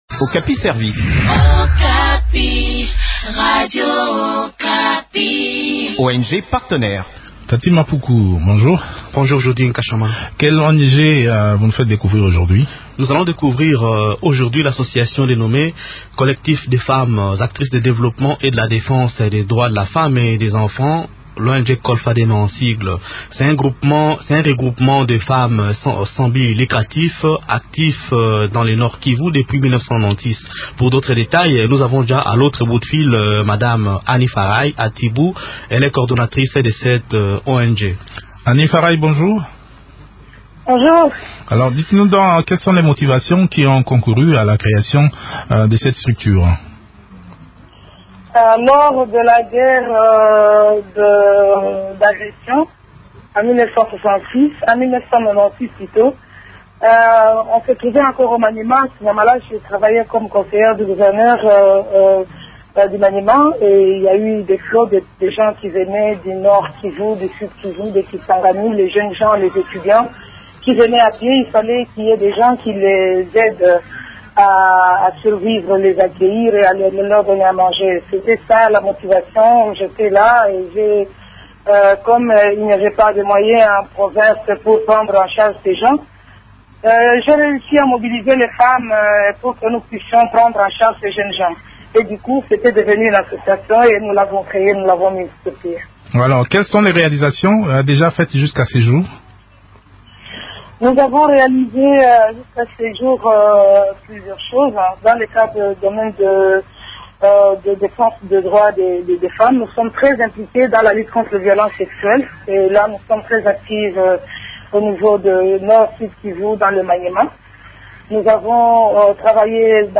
entretien